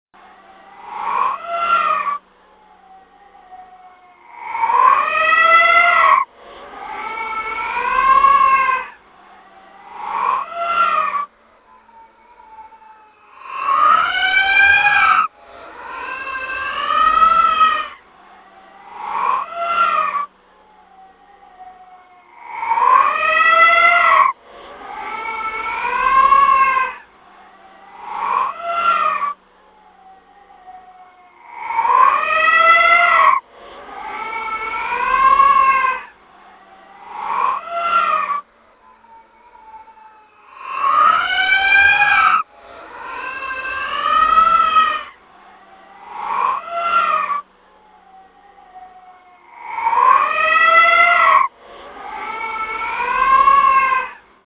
Horror Sound effects�@���|�̉���